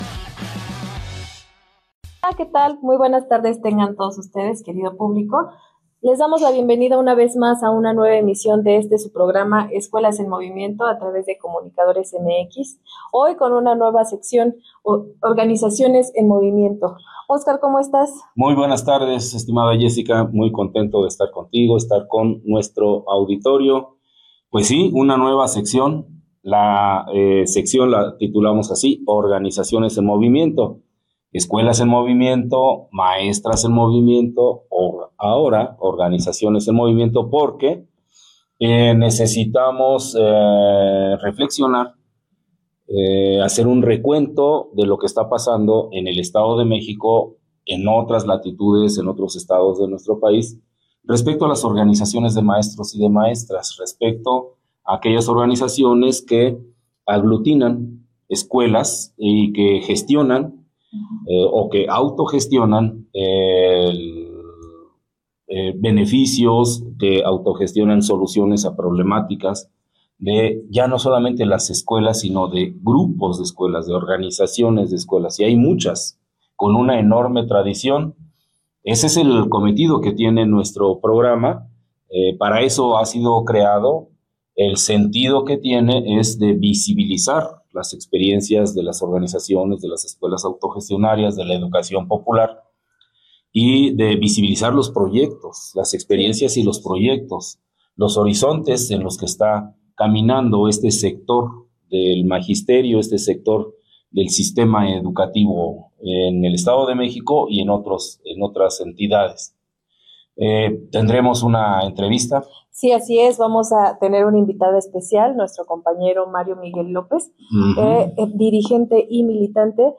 Programa Escuelas en Movimiento, transmitido en ComunicadoresMx el 26 Febrero 2026.